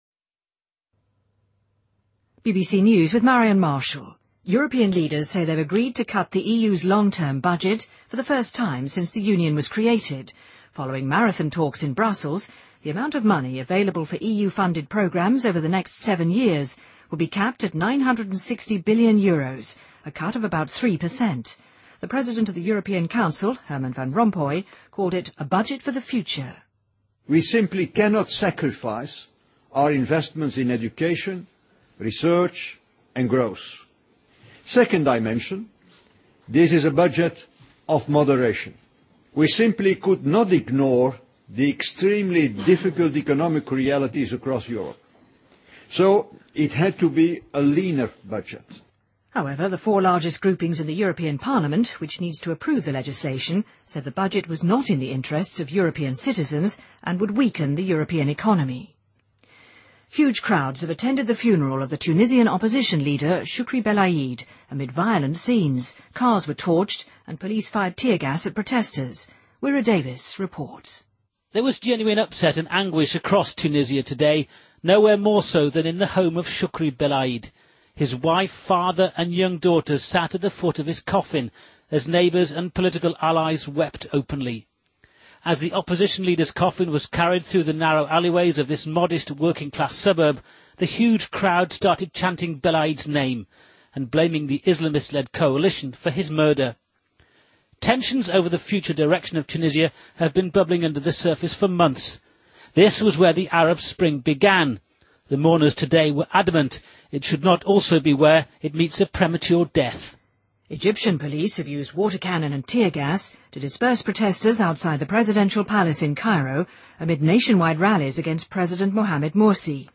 BBC news,2013-02-09